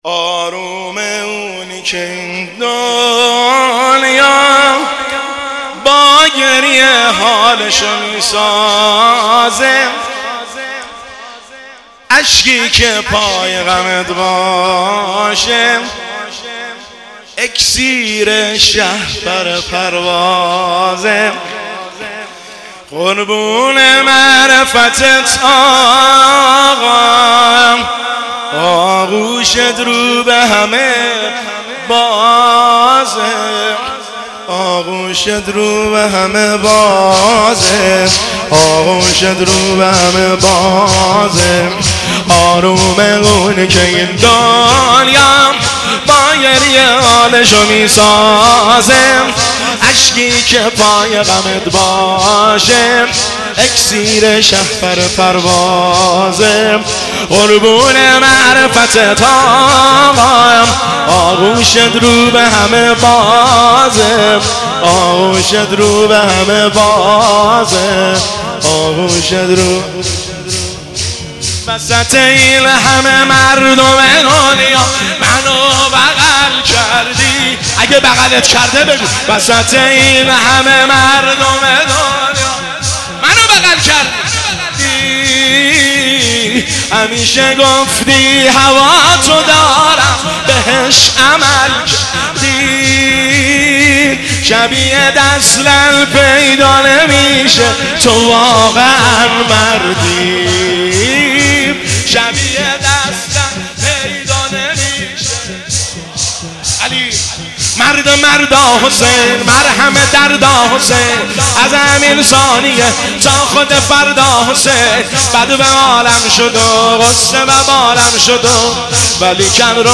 هیئت هفتگی 24 اردیبهشت 1404